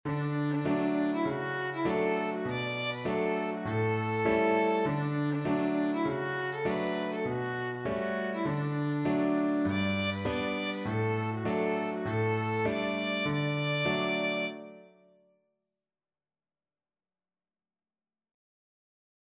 Violin
D major (Sounding Pitch) (View more D major Music for Violin )
2/4 (View more 2/4 Music)
Playfully =c.100
Easy Level: Recommended for Beginners with some playing experience
Traditional (View more Traditional Violin Music)
world (View more world Violin Music)
ame_ame_VLN.mp3